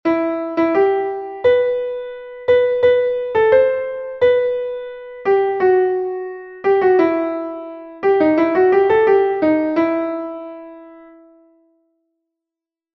Entoación a capella
Melodía 2/4 en Mi m